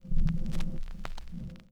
Record Noises
Record_End_8.aif